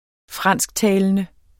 Udtale [ -ˌtæːlənə ]